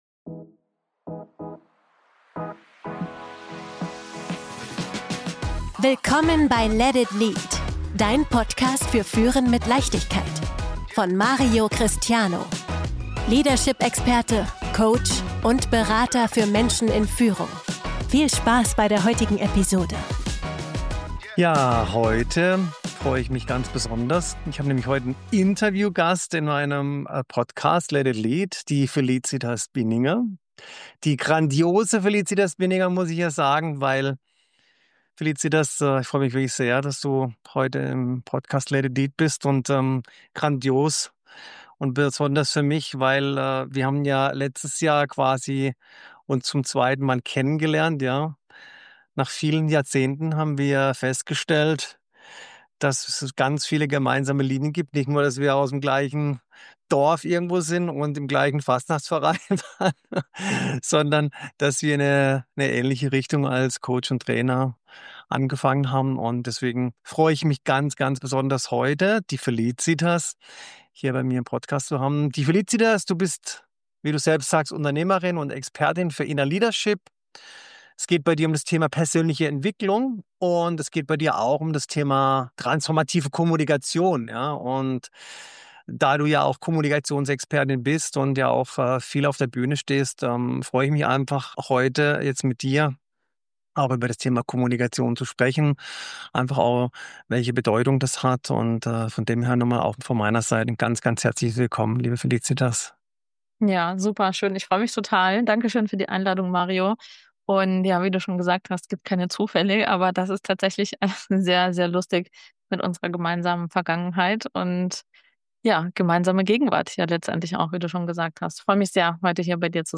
Was passiert, wenn eine Kommunikationstrainerin und ein Leadership-Coach über echte Führung sprechen?